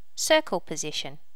Initial check in of the sounds for the notify plugin.
circle position.wav